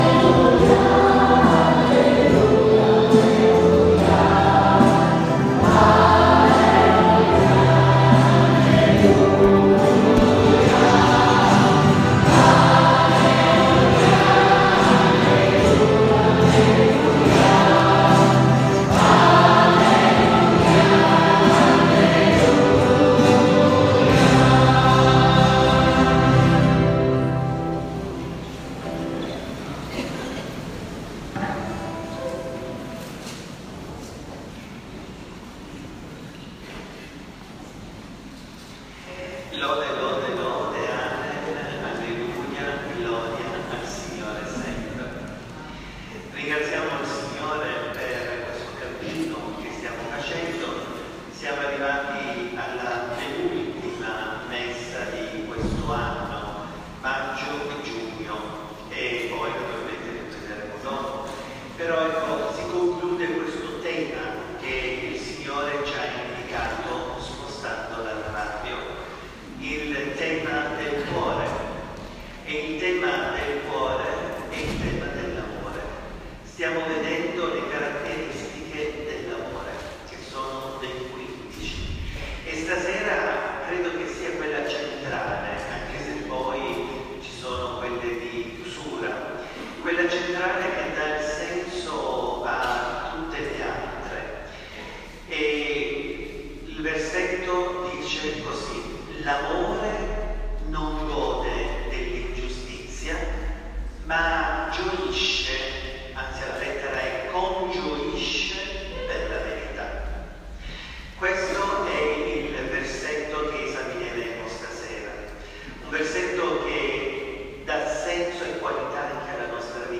L'Amore non gode per l'ingiustizia - Messa di Intercessione Novara